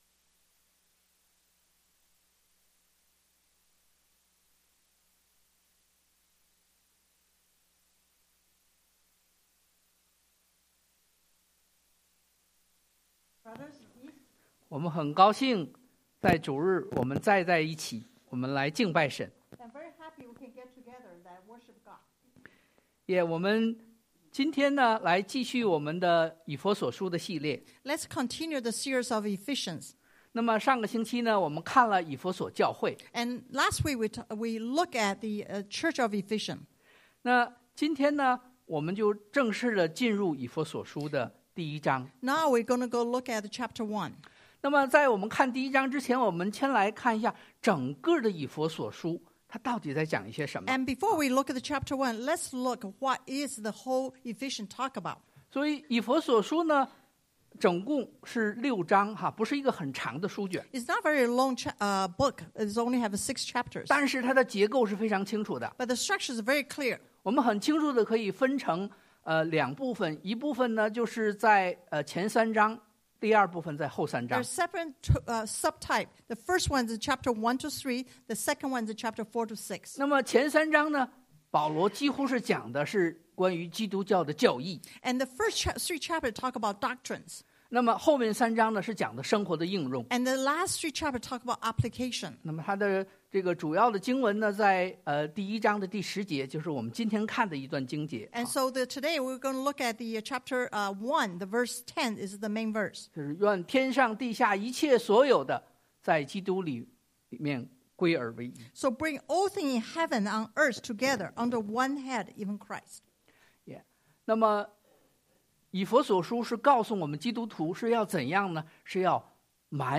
Eph 1:3-14 Service Type: Sunday AM Bible Text